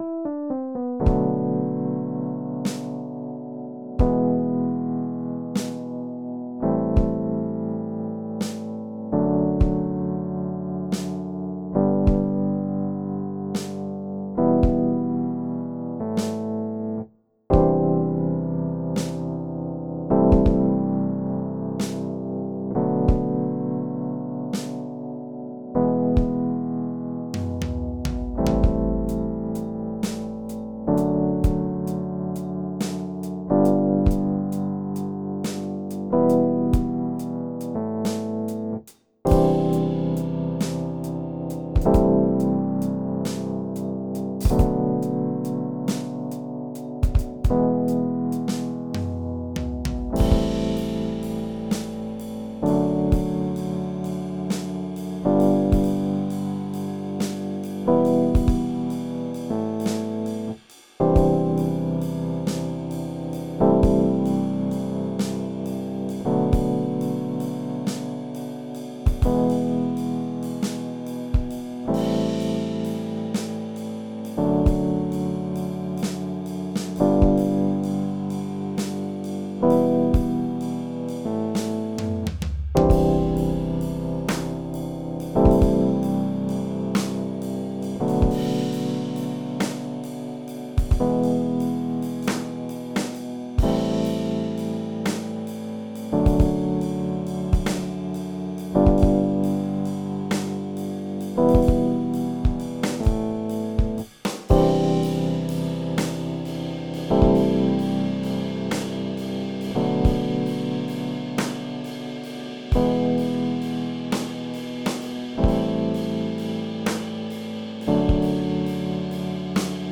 It's really rough,
but I love the chords.
Wurlitzer+Thing.wav